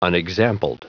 Prononciation du mot unexampled en anglais (fichier audio)
Prononciation du mot : unexampled